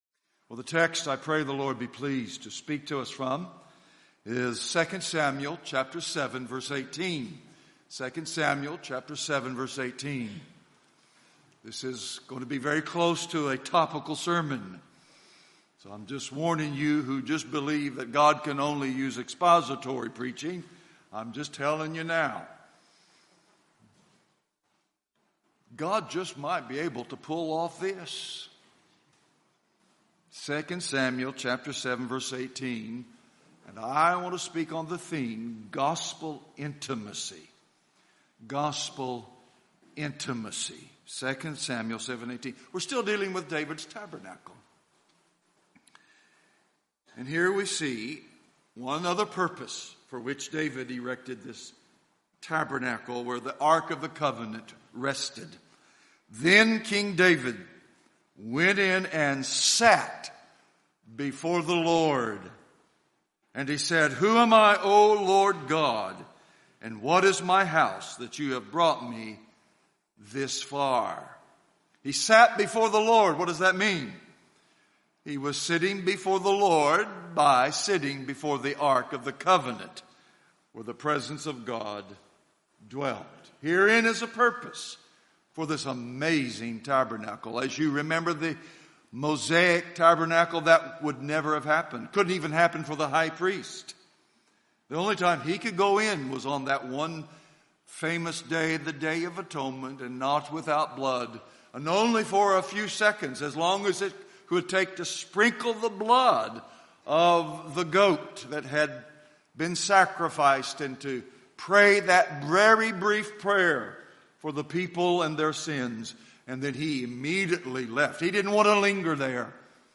2024 Fellowship Conference New York